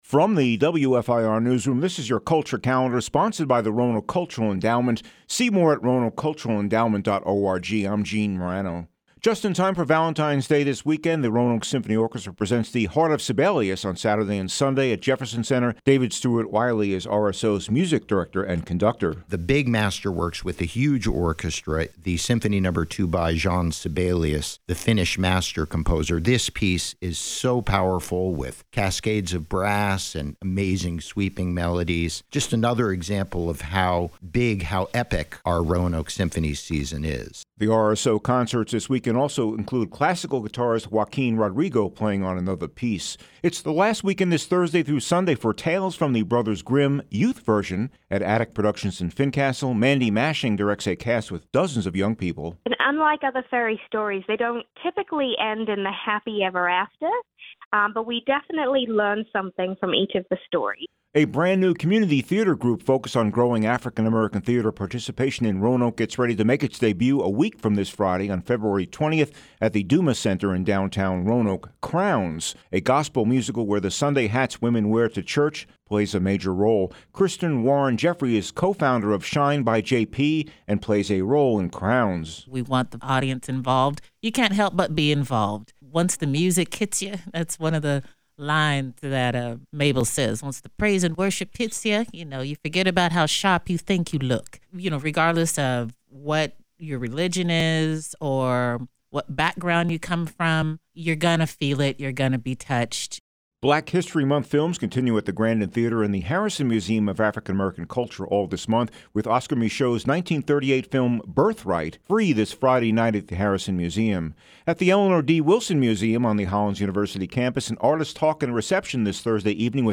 From the WFIR News room this is your Culture Calendar – With a look at some of the local events coming up this weekend and in the near future.